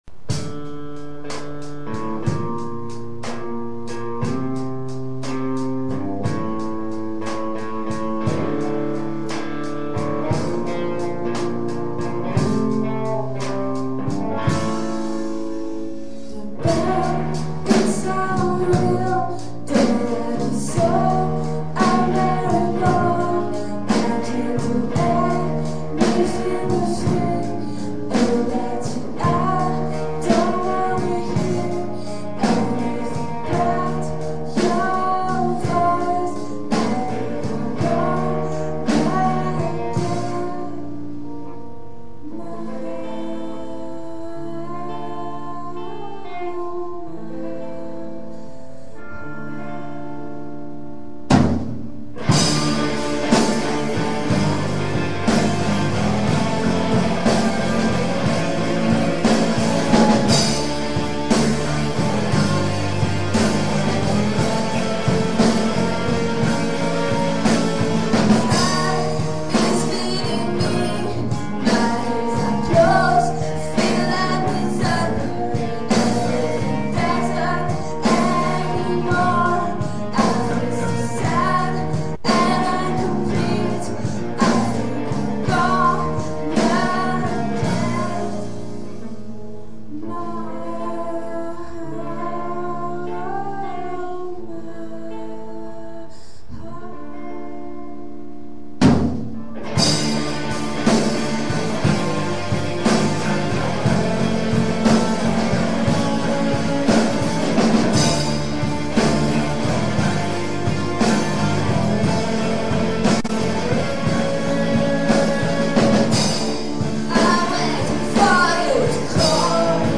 (Live)
Enregistrée en répète avec un MD...